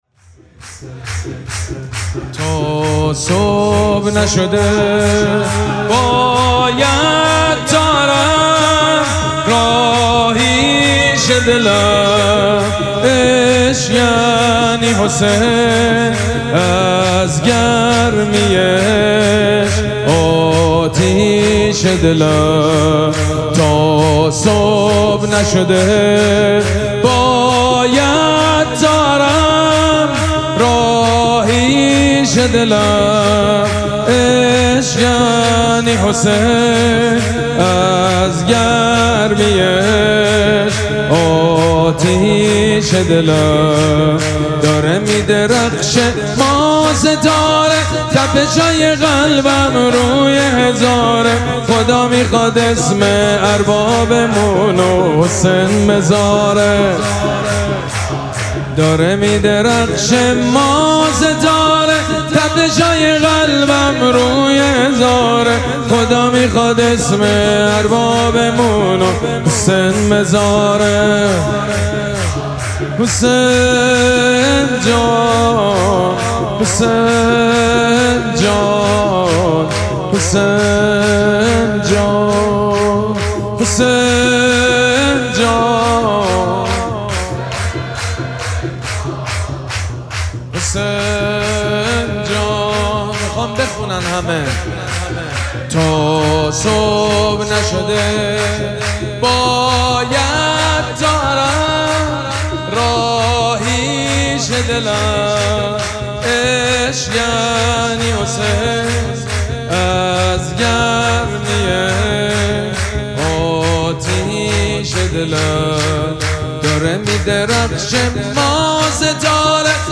شب اول مراسم جشن ولادت سرداران کربلا
سرود
حاج سید مجید بنی فاطمه